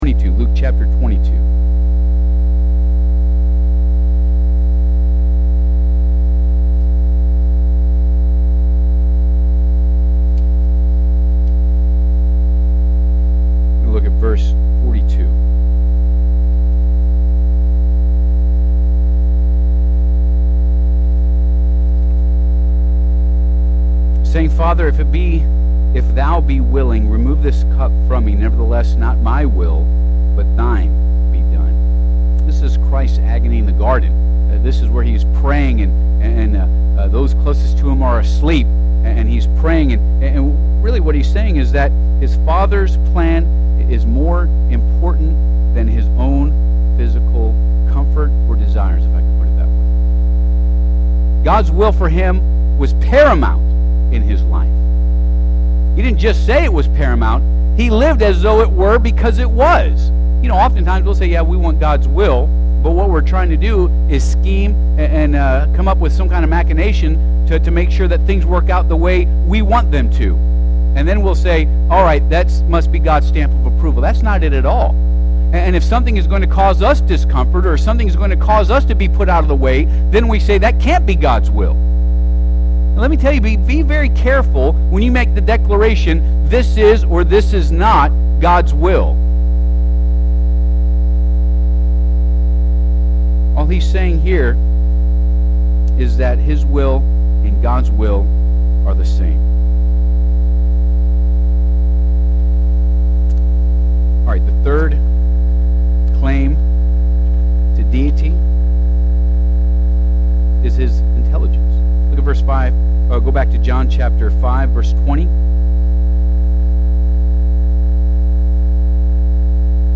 Passage: John 5 Service Type: Midweek Service Bible Text